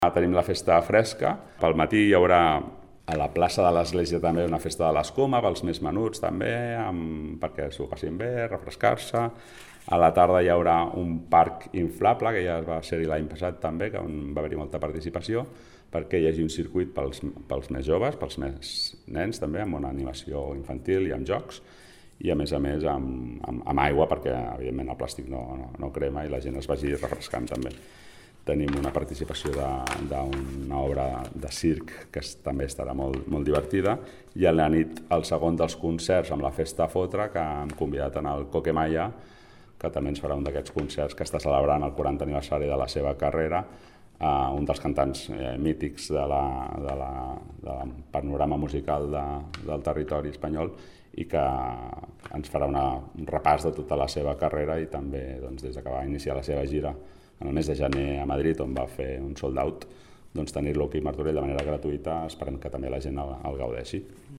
Sergi Corral, regidor de Cultura